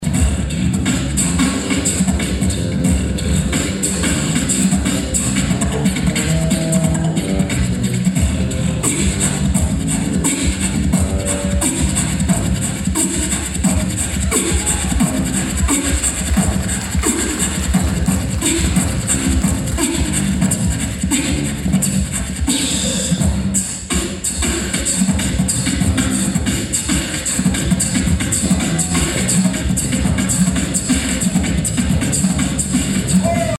Great music and giving hearts make for perfect combination during United Way benefit concert at Emporia State Thursday evening
From jazz, to blues, to pop and even beatboxing, a special benefit concert through Emporia State University’s music department had it all Thursday night.